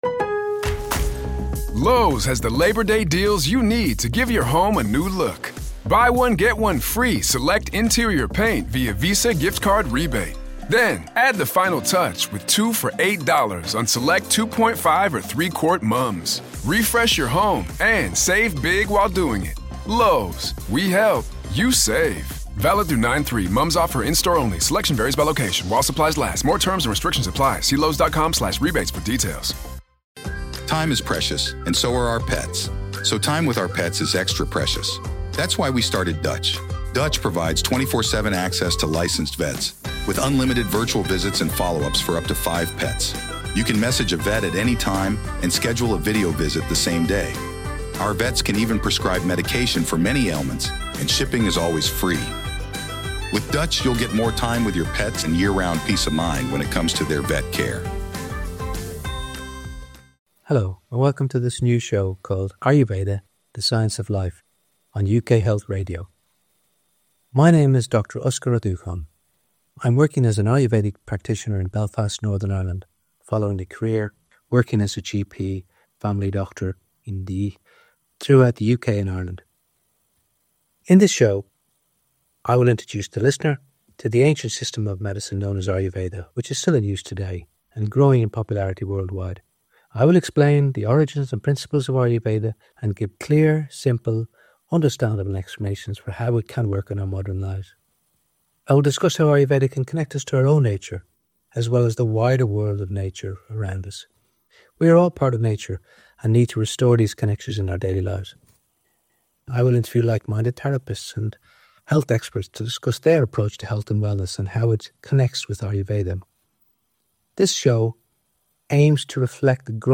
I'll interview therapists and health experts who share a holistic view of wellness. Ayurveda uses food and herbal remedies to address the root causes of illness, emphasising the link between physical symptoms and unresolved emotional issues.